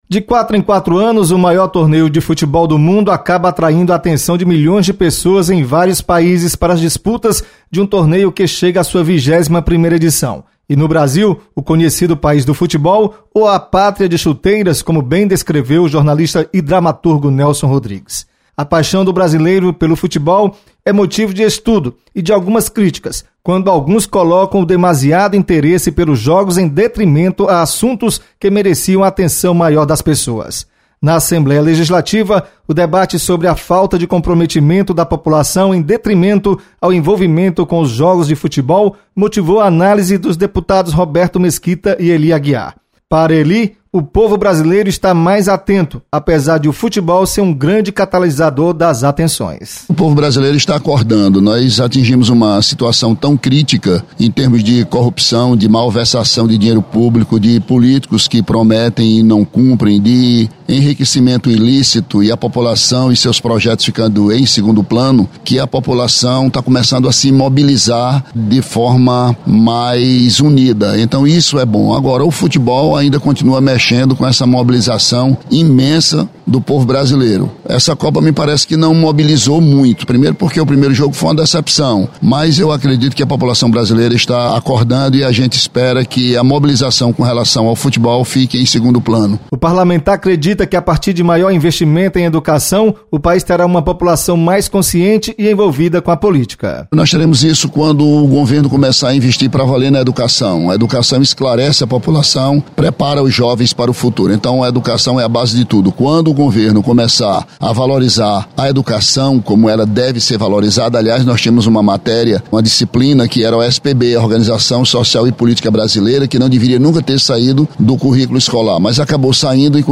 Deputados comentam sobre relação do brasileiro com política e futebol.